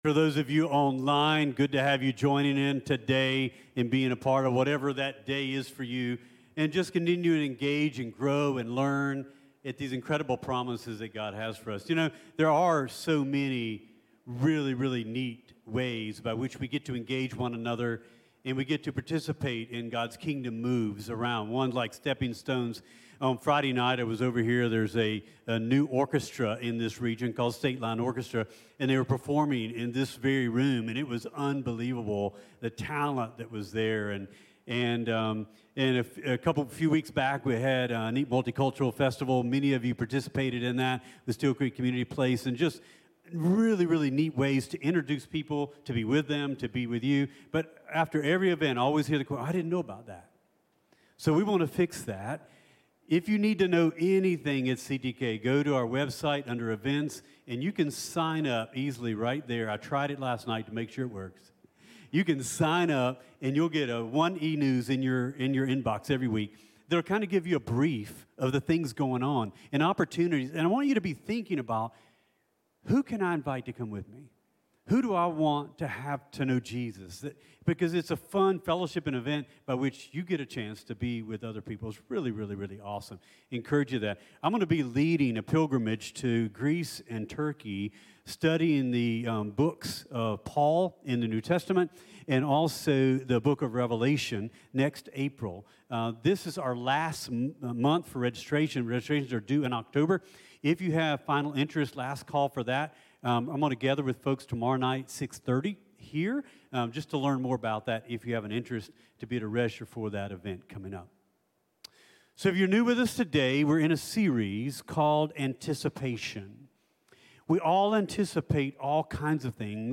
CtK-Sermon-Audio.mp3